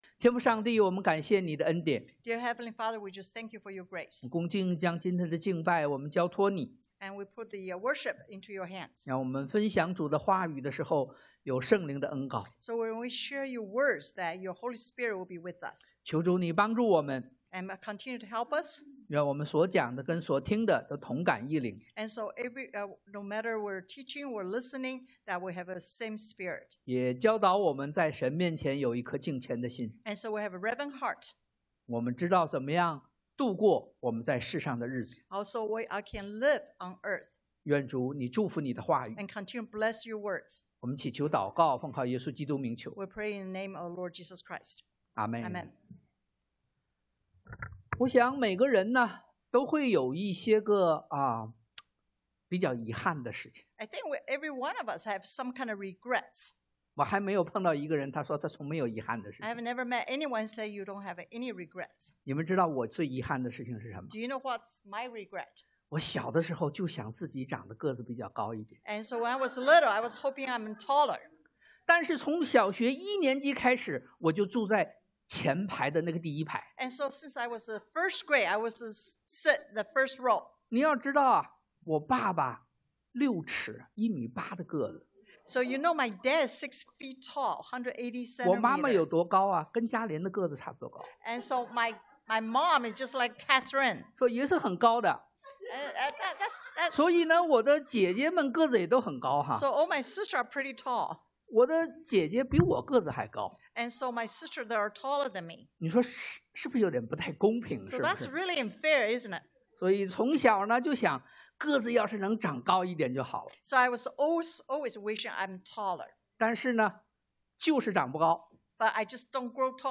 但 Dan 5:17-31 Service Type: Sunday AM 1.